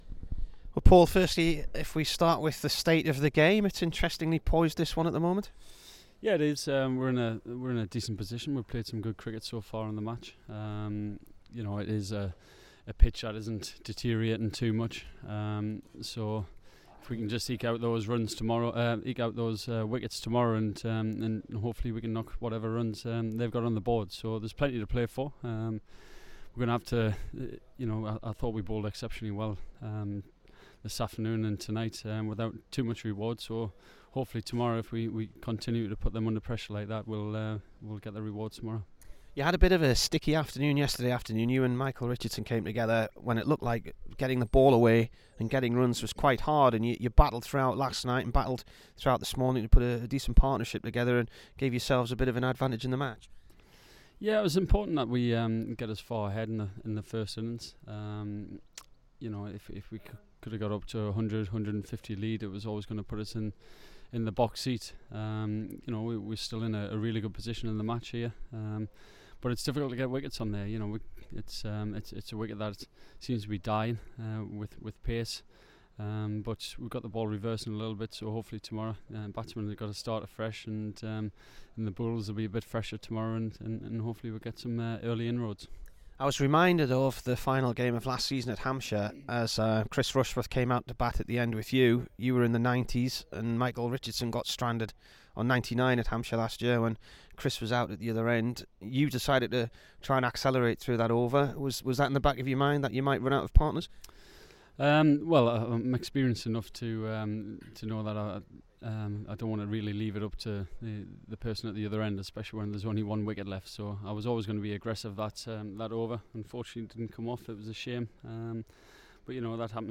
Here is the Durham captain after his 97 on day 3 v Gloucestershire.